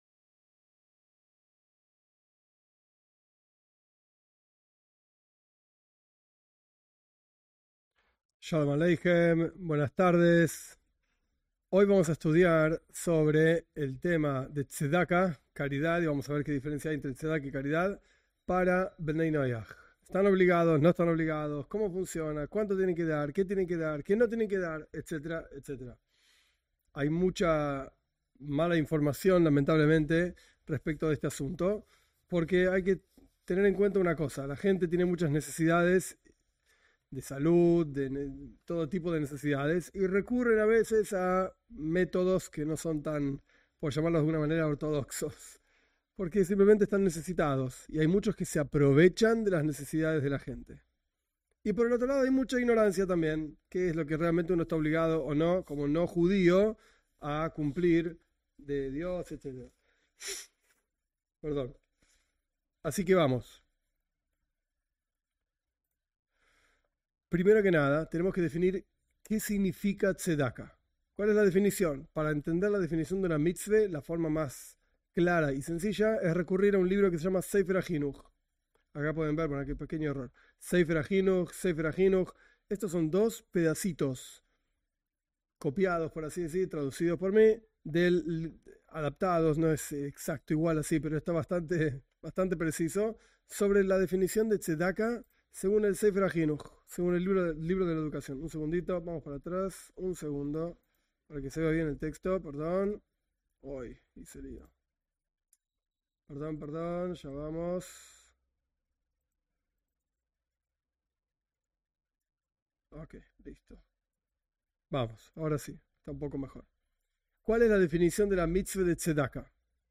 En esta clase se analiza en profundidad la relación entre el precepto de Tzedaká (caridad) y Bnei Noaj.